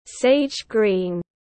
Sage green /ˌseɪdʒ ˈɡriːn/